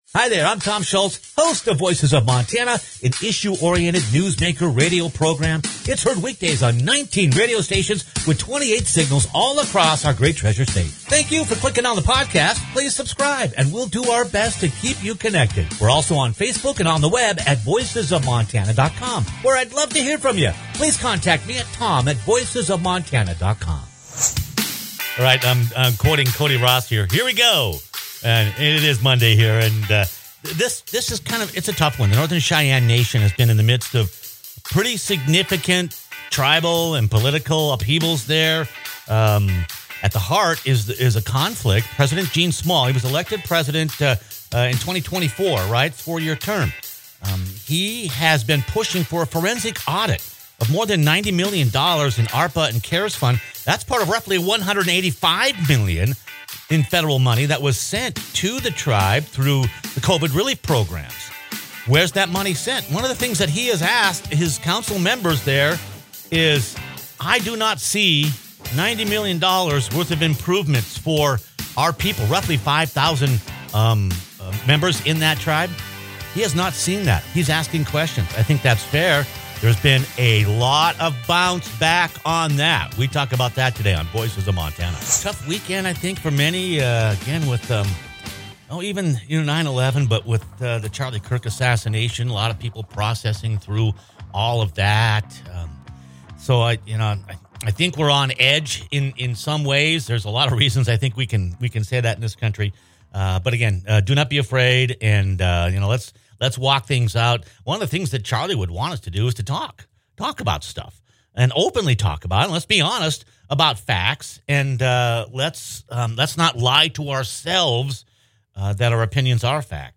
The conflict playing out at Northern Cheyenne Tribal Headquarters began months ago, when President Gene Small attempted to get the tribal council to initiate a financial audit of more than $90 million it was awarded by the federal government through ARPA and COVID programs. President Small joins the program to discuss the latest developments.